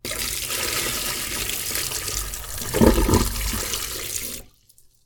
台所
流しに水捨てる１
water_into_sink1.mp3